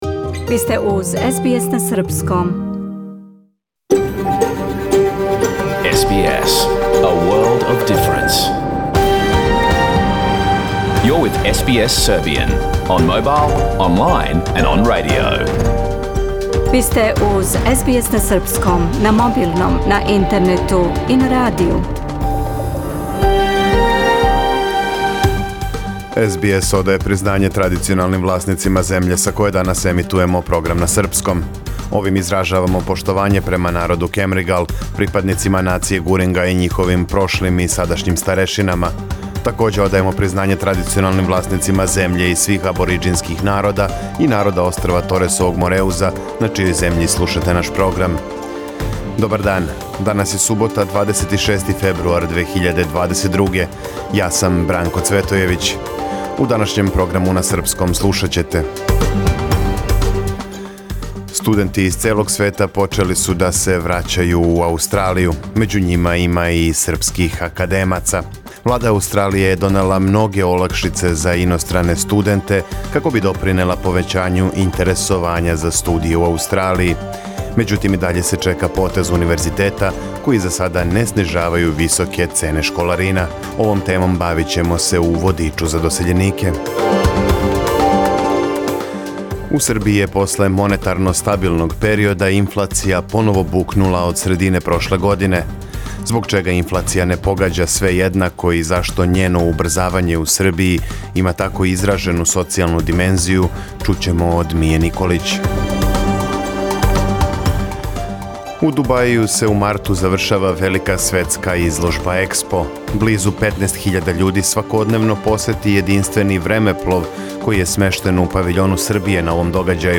Програм емитован уживо 26. фебруара 2022. године